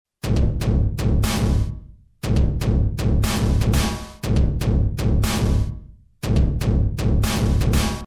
The power of modern software effects allows for modelling of weird and wonderful environments that used to have to be created as real world environments.
Old-School Oil Tank Reverb
hfx6_8_Old_School_Oil_Tank_Reverb.mp3